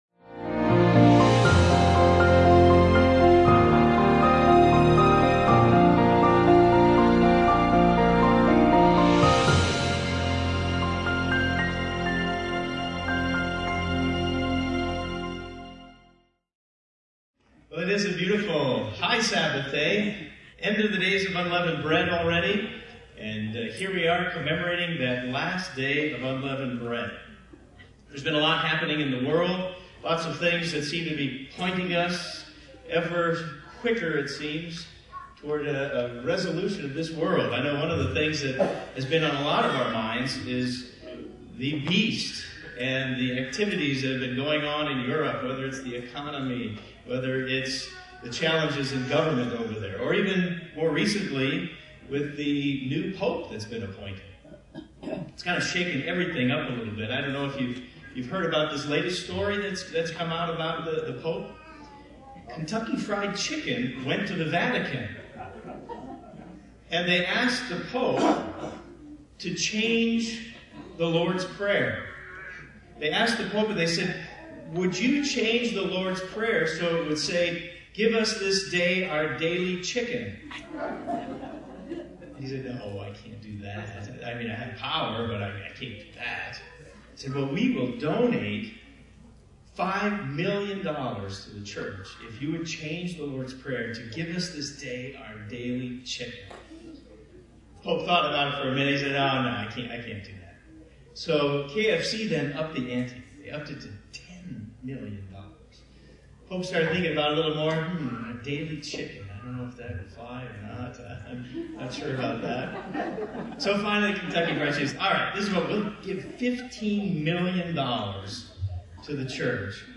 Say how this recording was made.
Sermon given during the last Day of Unleavened Bread.